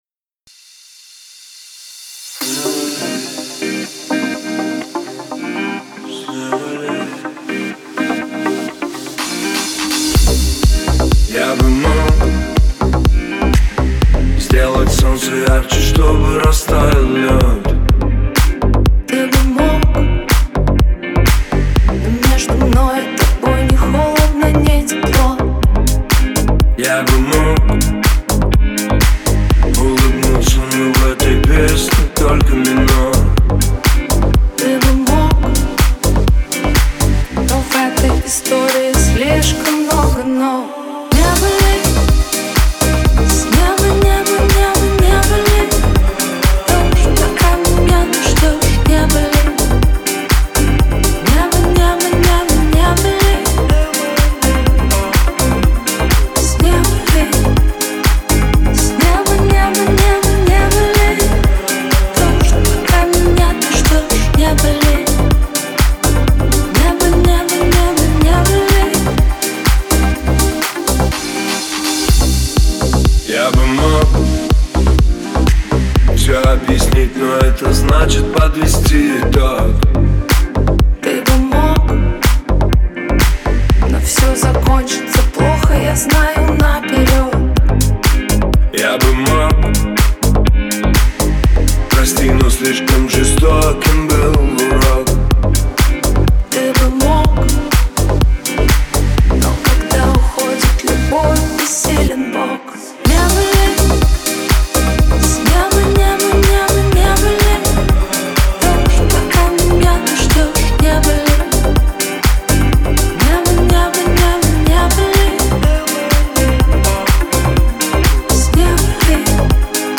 мелодичные вокалы